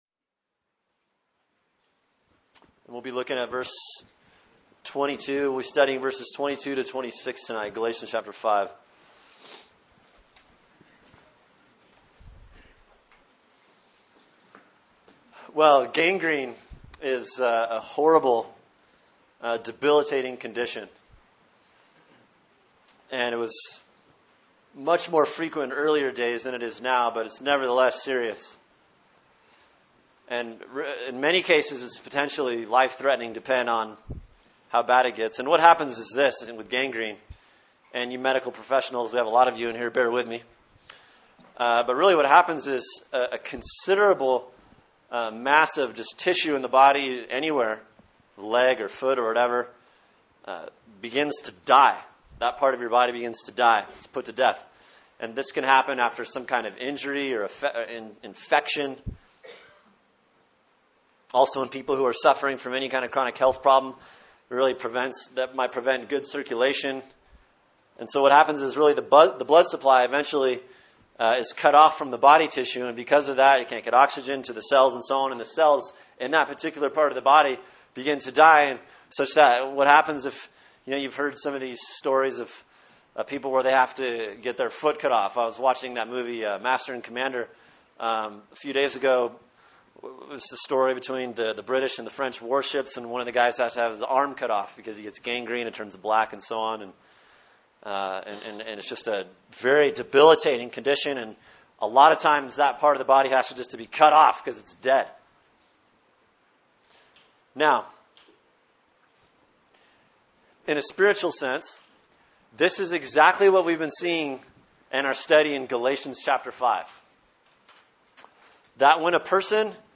Sermon: Galatians 5:22-26 “A Soul Check-Up (part 2)” | Cornerstone Church - Jackson Hole